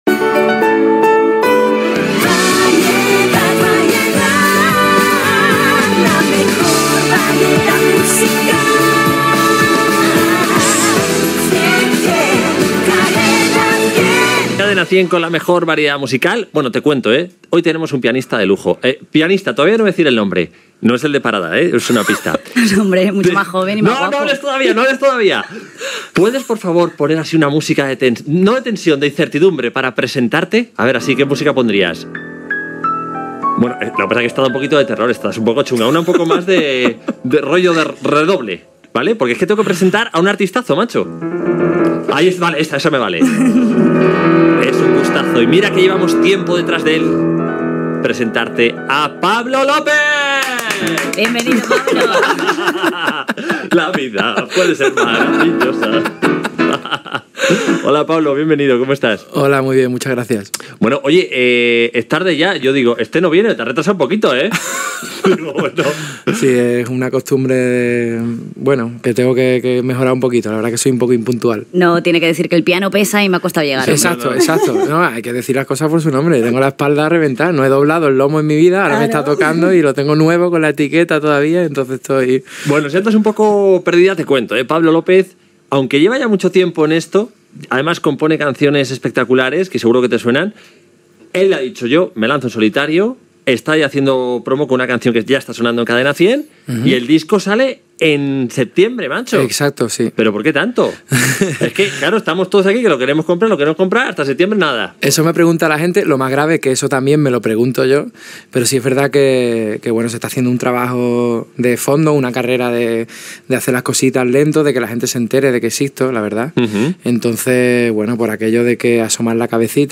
Indicatiu de l'emissora, presentació i entrevista al pianista i cantant Pablo López
Musical